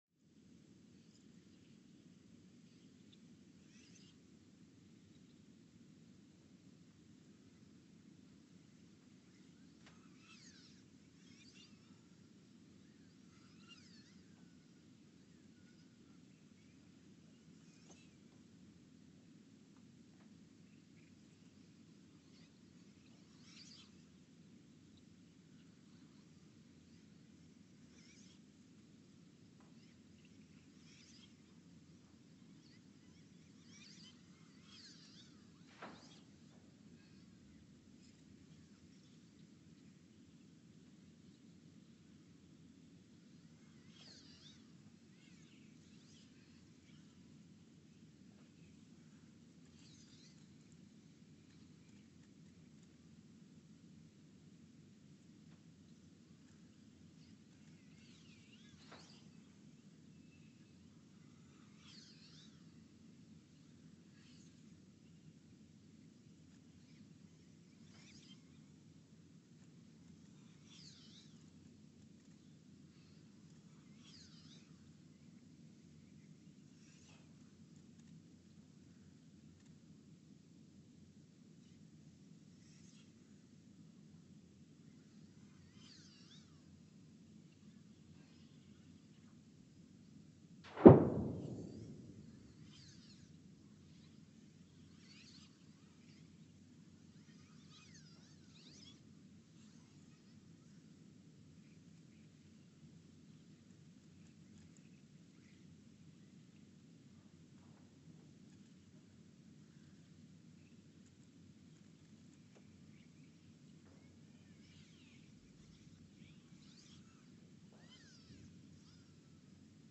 The Earthsound Project is an ongoing audio and conceptual experiment to bring the deep seismic and atmospheric sounds of the planet into conscious awareness.
Station : ULN (network: IRIS/USGS ) at Ulaanbaatar, Mongolia Sensor : STS-1V/VBB
Speedup : ×900 (transposed up about 10 octaves)
Loop duration (audio) : 11:12 (stereo)